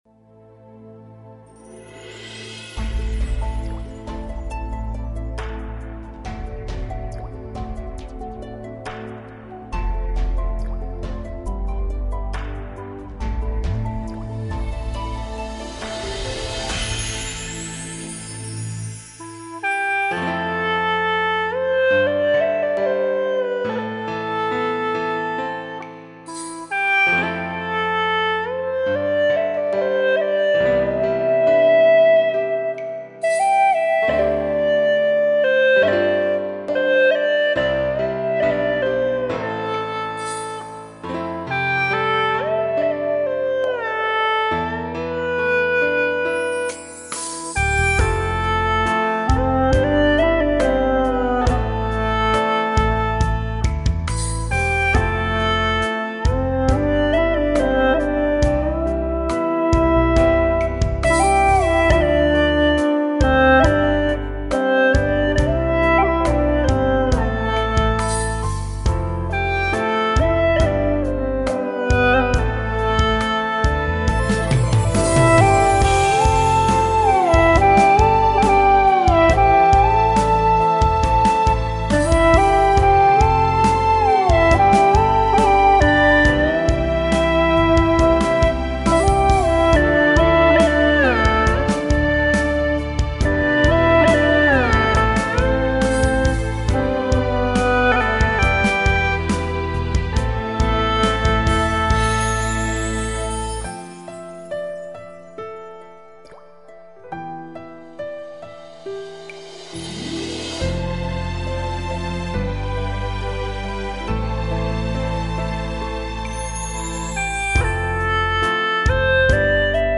调式 : C 曲类 : 古风
用葫芦丝演奏也别有一番味道。
【大小C调】